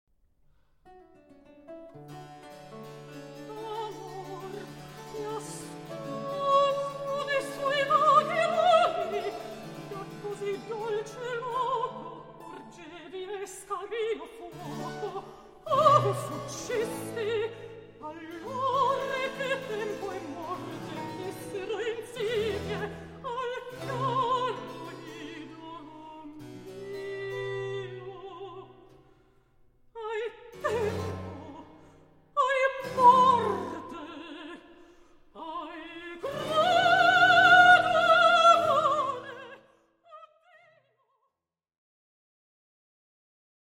baroque repertoire